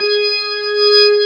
55o-org16-G#4.wav